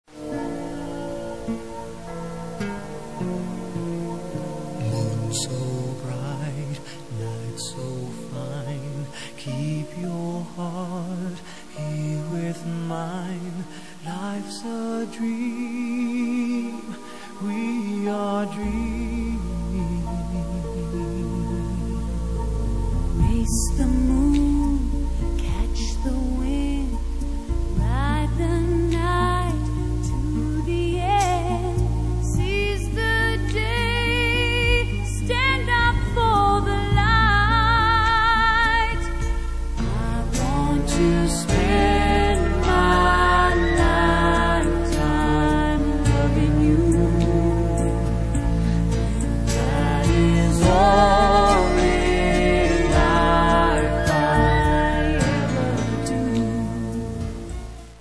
サルサの音楽、ダンスビデオ
早いリズムの音楽がいい。